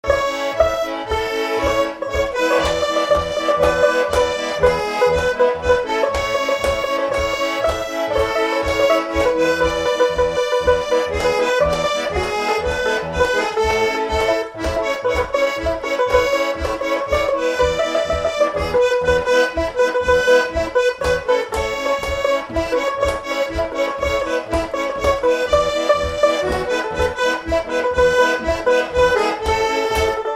Chanson française
Instrumental
Pièce musicale inédite